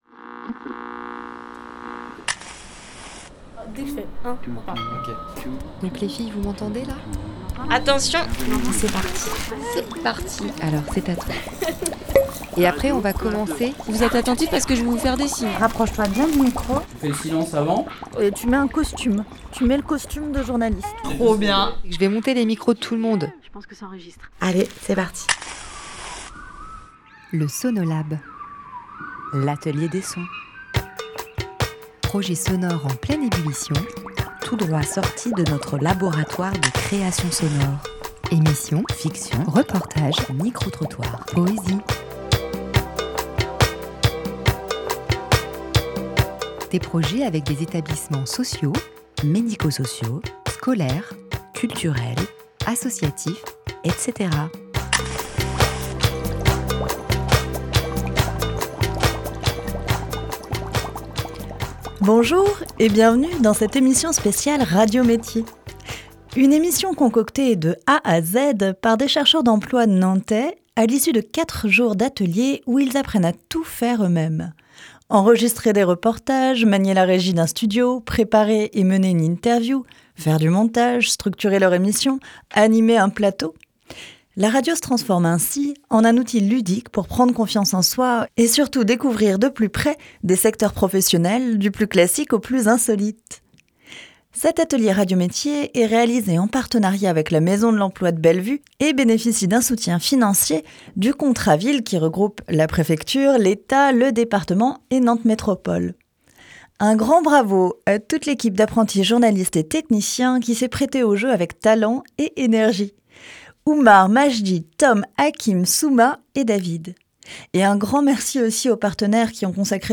Radio métiers est une émission concoctée de A à Z par des chercheurs d’emploi Nantais à l’issu de 4 jours d’ateliers où ils apprennent à tout faire eux-mêmes : enregistrer des reportages, manier la régie d’un studio, préparer et mener une interview, faire du montage, structurer leur émission, animer un plateau radio. La radio se transforme ainsi en outil ludique pour prendre confiance en soi et découvrir de plus près des secteurs professionnels, du plus classique au plus insolite.